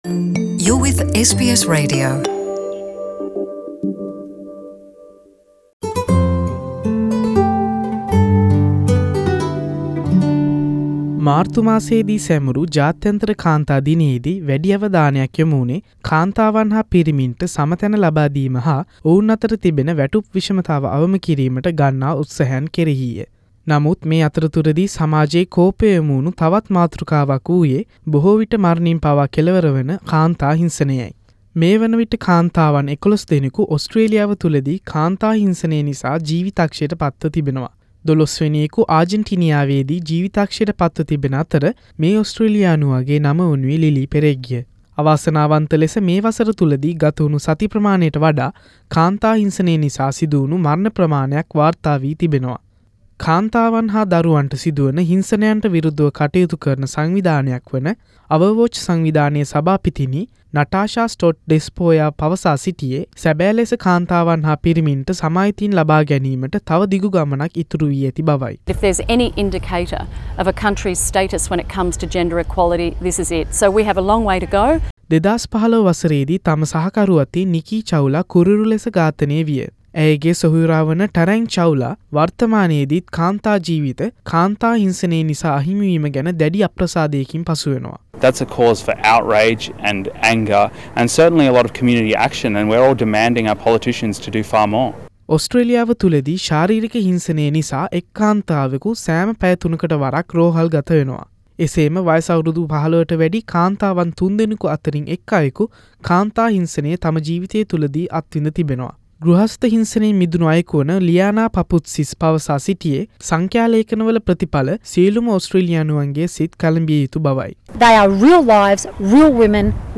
කාන්තා හිංසනය පිළිබඳව සමාජයේ විවිධ පුද්ගලයින් දරනා අදහස් ඇතුලත් විශේෂාංගයක් SBS සිංහල සේවය වෙතින්..